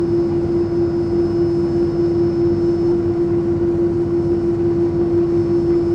A32X: New NEO idle sound